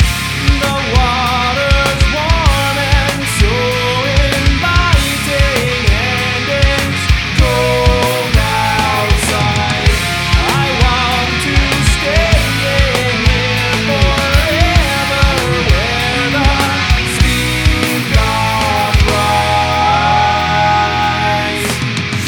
showersong.ogg